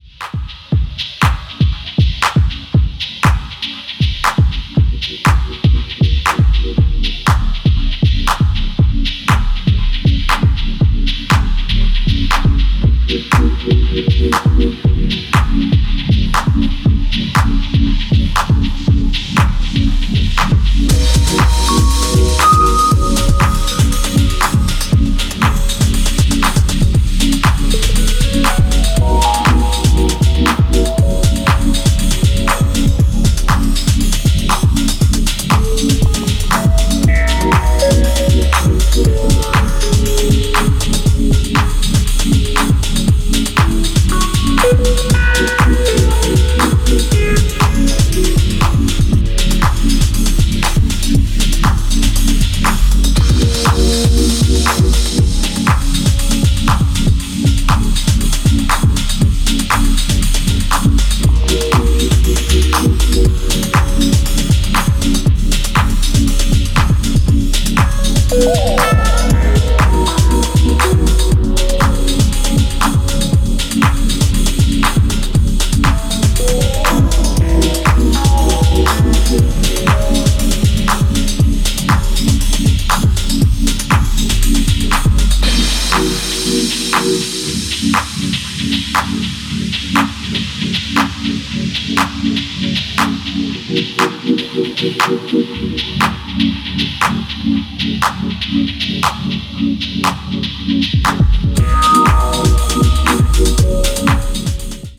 a mini album full of deeply rooted house music